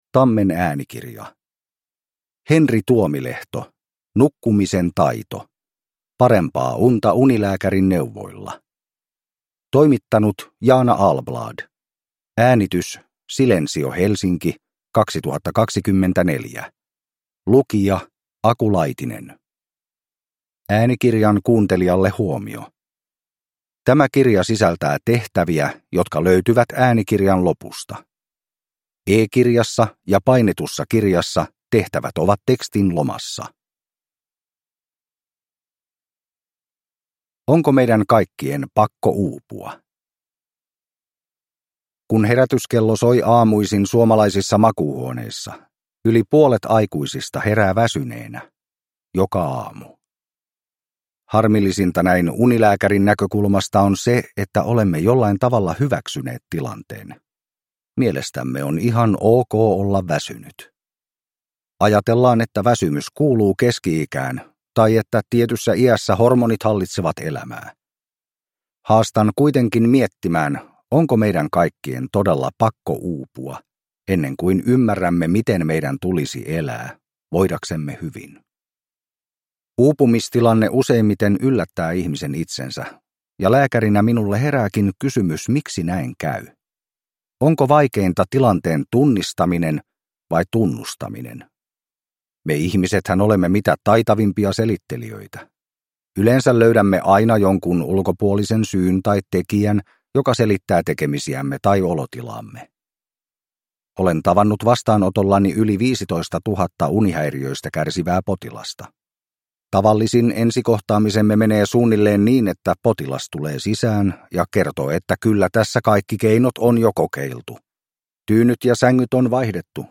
Nukkumisen taito – Ljudbok